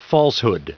Prononciation du mot falsehood en anglais (fichier audio)
Prononciation du mot : falsehood